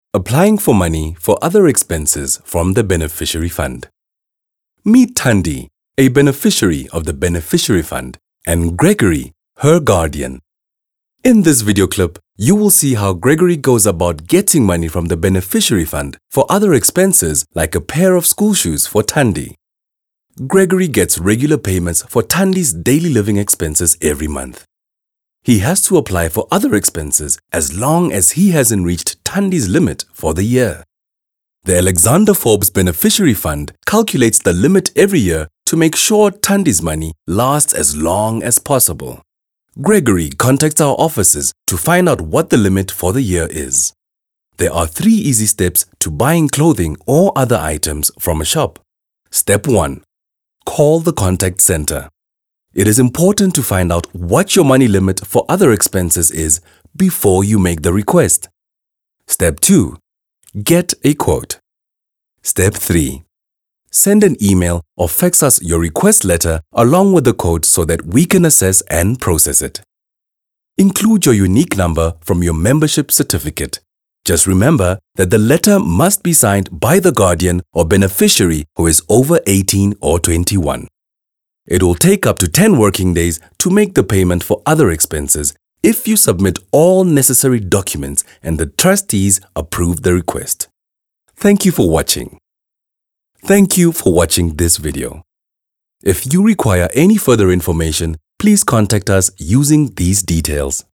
South Africa
authentic, bright, captivating, charming, clear, crisp
My demo reels